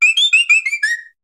Cri de Plumeline Style Buyō dans Pokémon HOME.
Cri_0741_Buyō_HOME.ogg